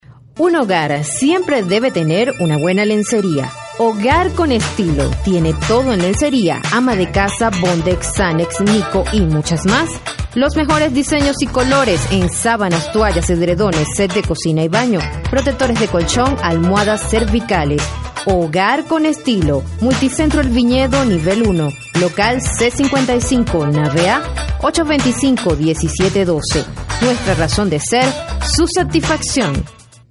Voz joven, experiencia en grabación de comerciales, voz en off, contestadoras empresariales, programas de radio y animación en vivo
Sprechprobe: eLearning (Muttersprache):
Young voice, experience in commercial recording, voice over, business answering, radio programs and live animation